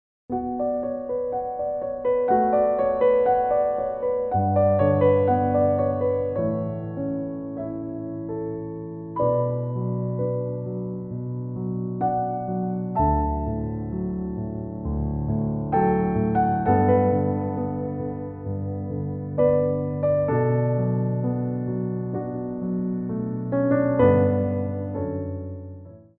Adage
4/4 (8x8)